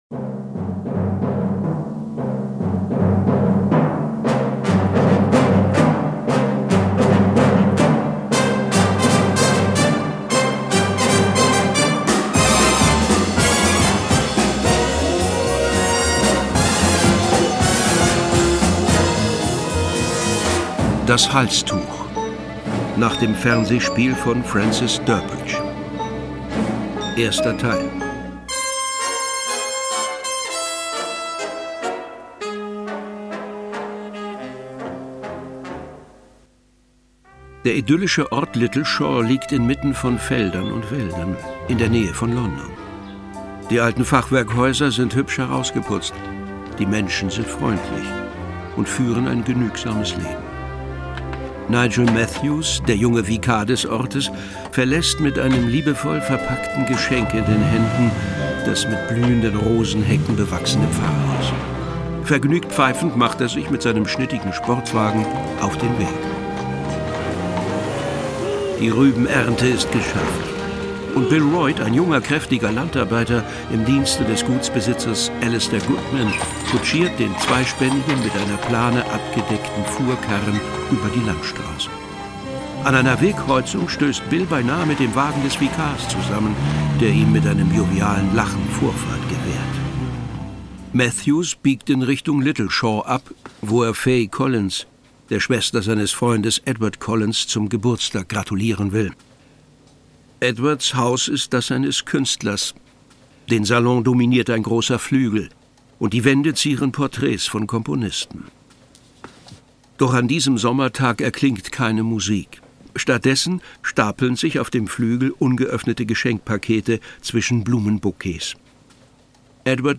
Filmhörspiel mit Heinz Drache, Horst Tappert u.v.a. (1 mp3-CD)
Horst Tappert, Heinz Drache (Sprecher)
Ein Filmhörspiel mit den Kult-Sprechern Heinz Drache und Horst Tappert.